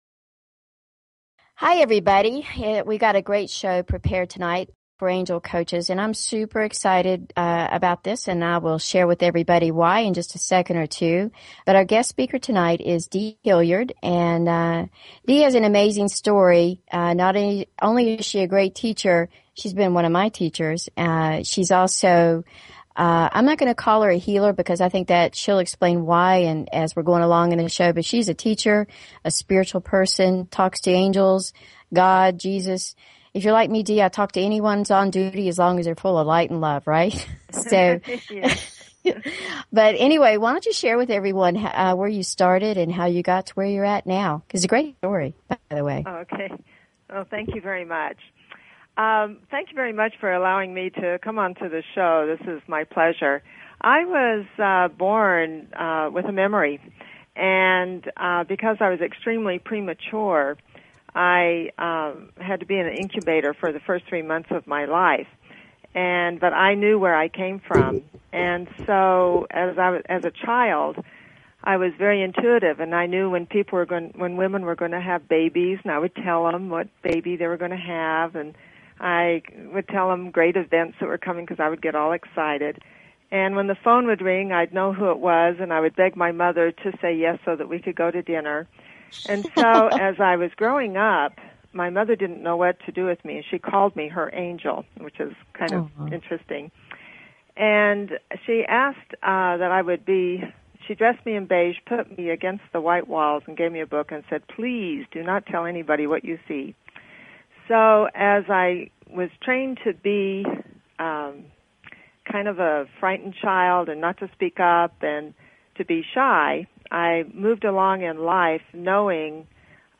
Talk Show Episode, Audio Podcast, Angel_Coaches and Courtesy of BBS Radio on , show guests , about , categorized as